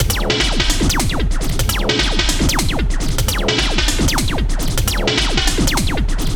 INDUKTLOOP 1.wav